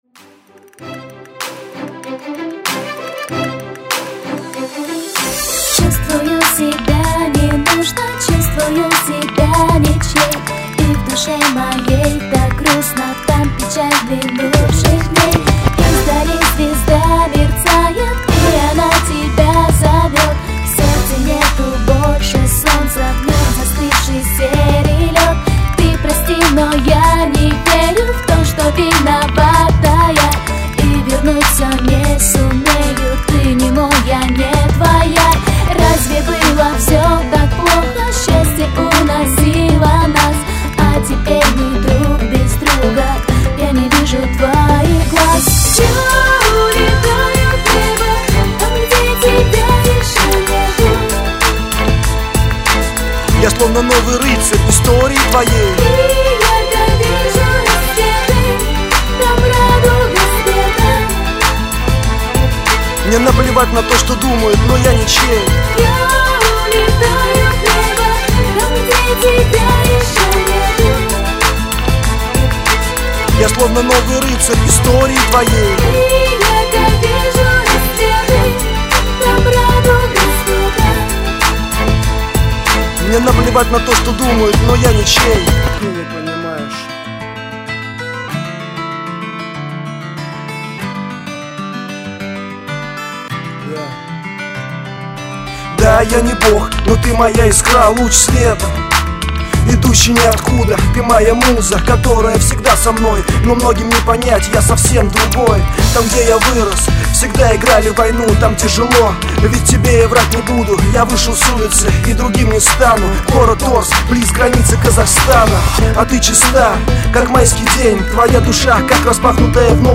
RnB demo Категория: Написание музыки